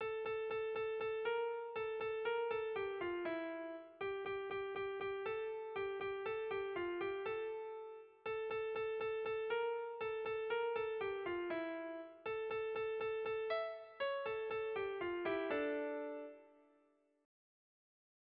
Tragikoa
Zortziko txikia (hg) / Lau puntuko txikia (ip)
A-B--A-C